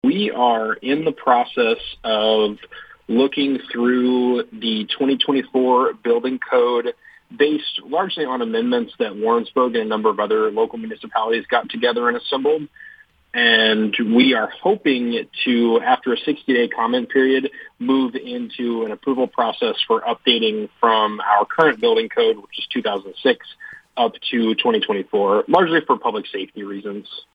City Administrator Cameron Jackson says the board is interested in adopting the 2024 International Building Code.